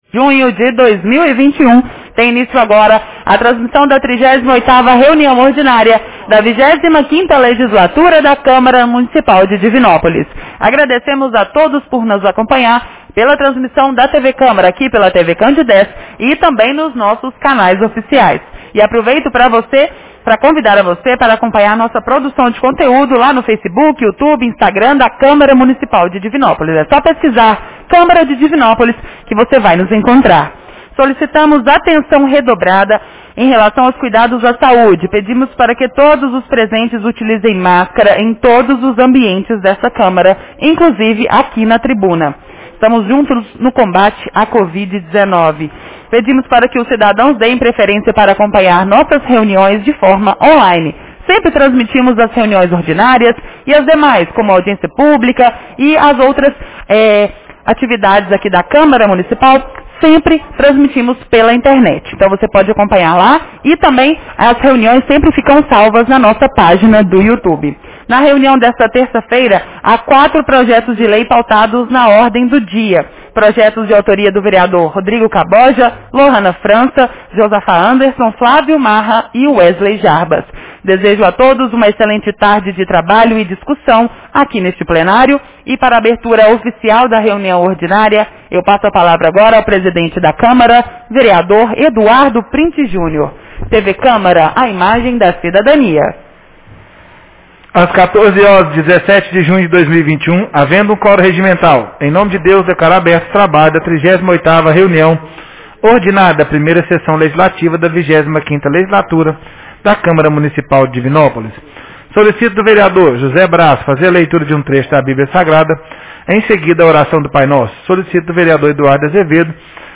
Reunião Ordinária 38 de 17 de junho 2021